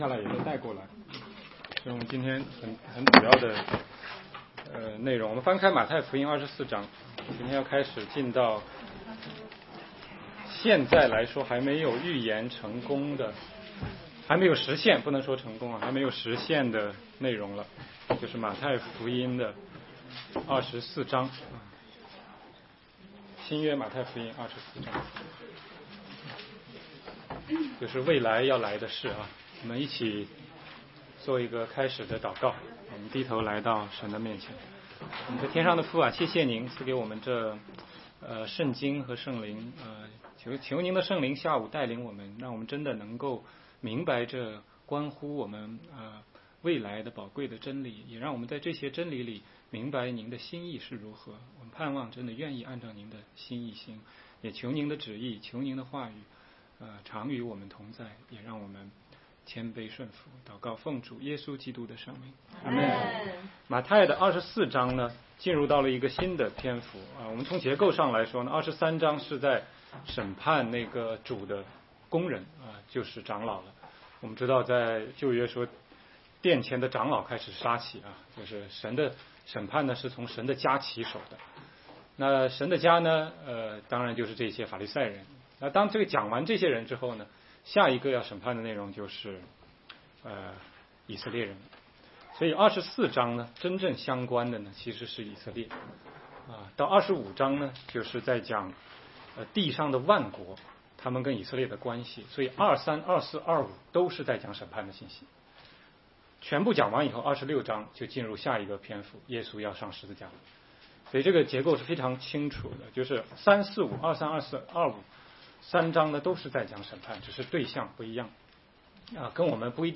16街讲道录音 - 马太福音24章1-8节：主预言以色列的未来